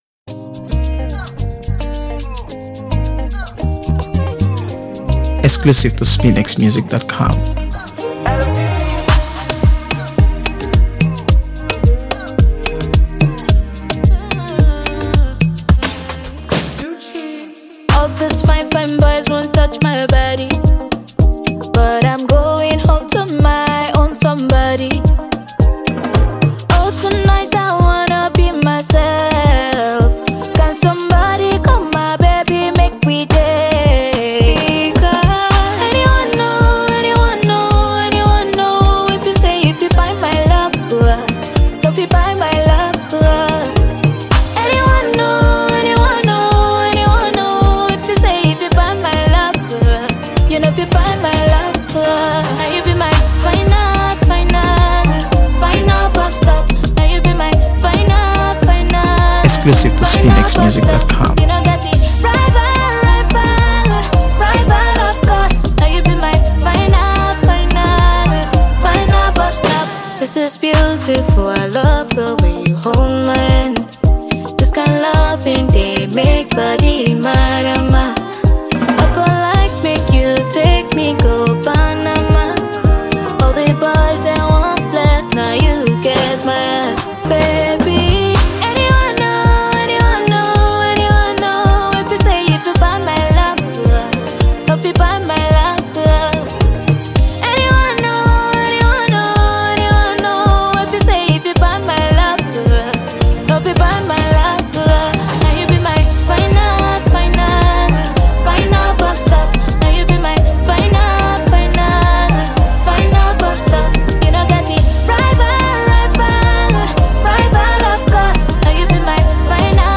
AfroBeats | AfroBeats songs
Built on vibrant percussion and melodic synths